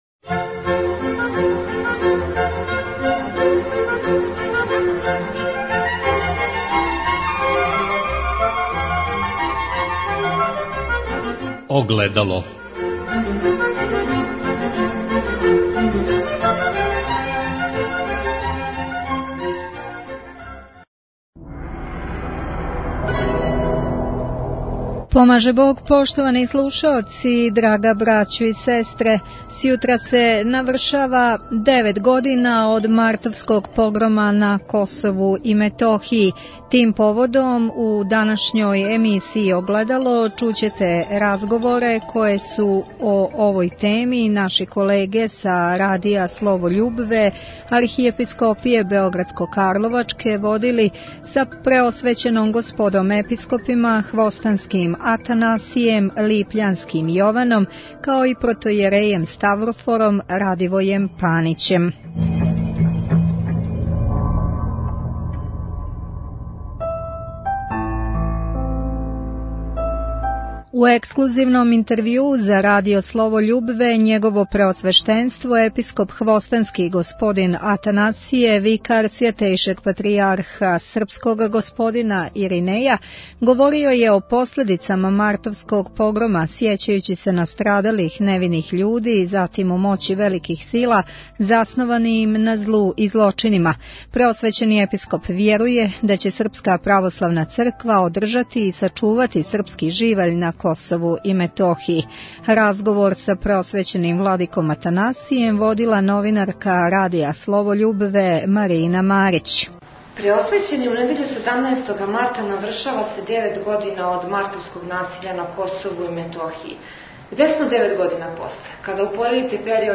У емисији Огледало доносимо разговоре које су наше колеге са Радија "Слово љубве" водили са Преосвећеном Господом Епископима Хвостанским Атанасијем и Липљанским Јованом